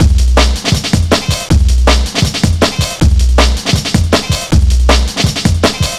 Index of /90_sSampleCDs/Zero-G - Total Drum Bass/Drumloops - 1/track 09 (160bpm)